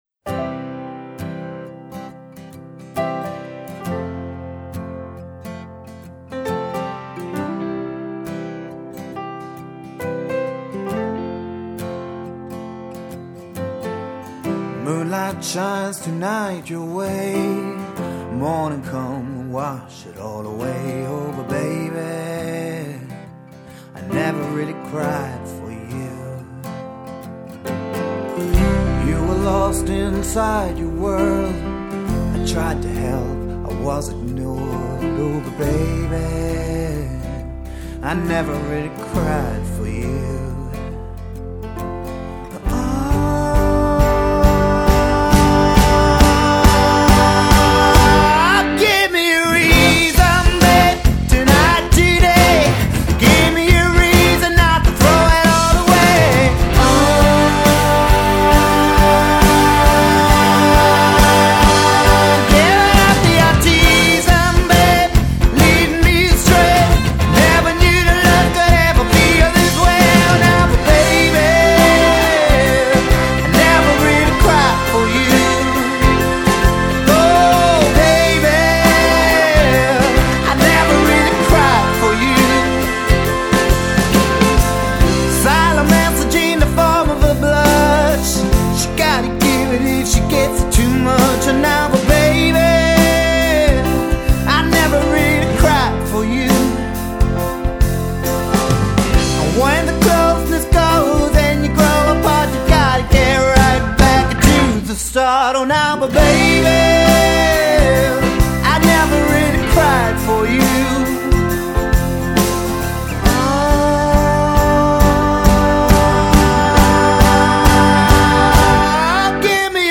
Never Really Cried. (pop/rock)